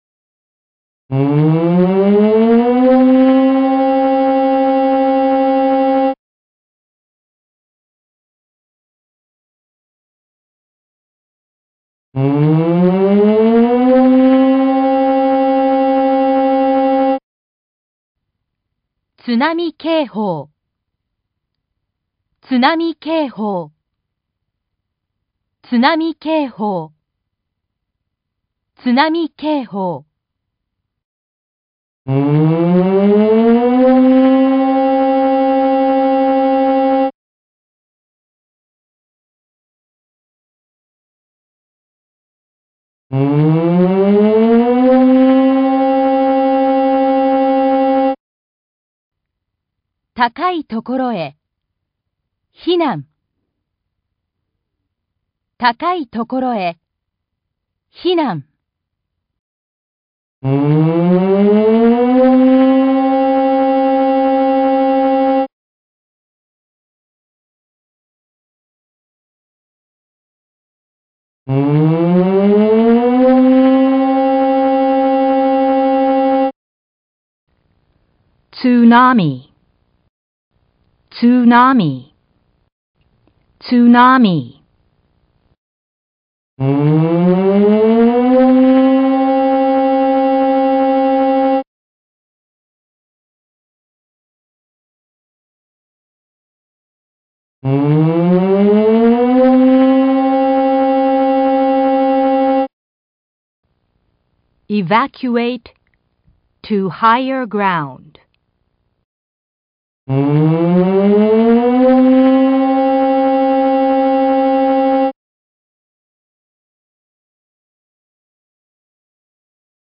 根據氣象廳發布的大海嘯警報、海嘯警報、海嘯警報,設置的室外揚聲器將自動播放海嘯警報、避難勸告等緊急資訊。
1. 警報(2次)
約5秒(約6秒秋)
※播出1～8 3套。另外,各套餐還播放了不同語言(英語、華語、韓語)催促避難的語言。
※9的汽笛只在第3局播放。
海嘯警報音源(1m<海嘯的預測高度≤3m)(音樂文件(MP3):5648KB)